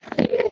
minecraft / sounds / mob / endermen / idle2.ogg